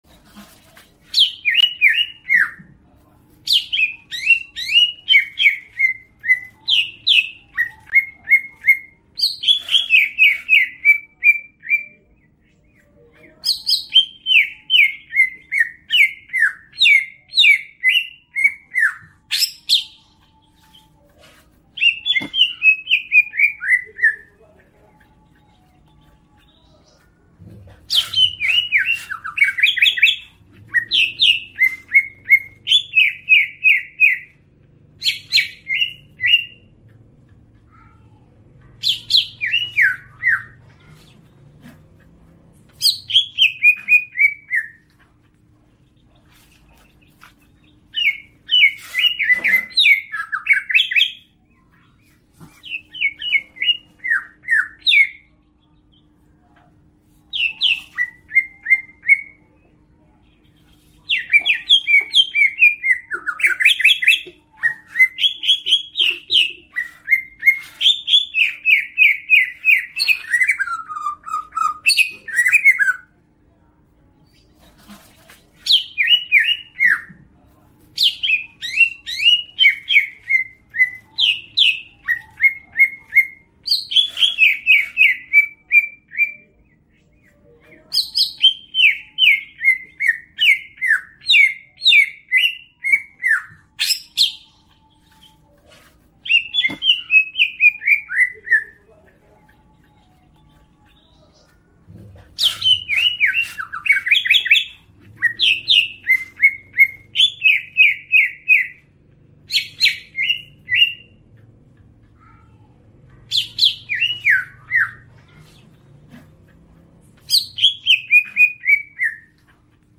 Kategori: Suara burung